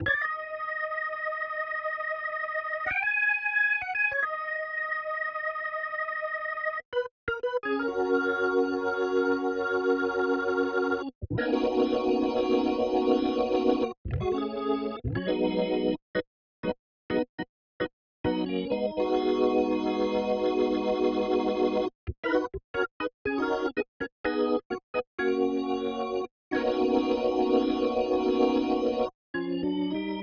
14 organ B2.wav